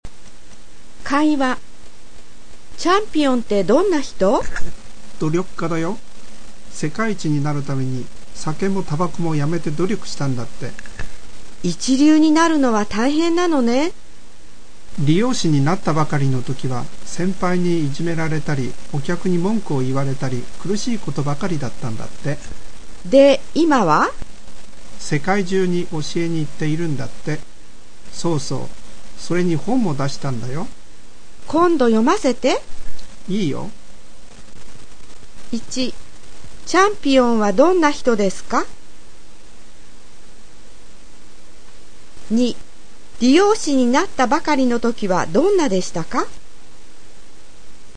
【会話】(conversation)